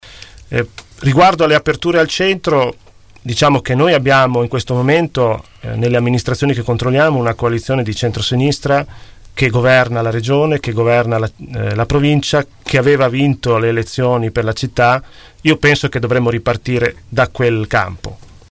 ospiti dei nostri studi.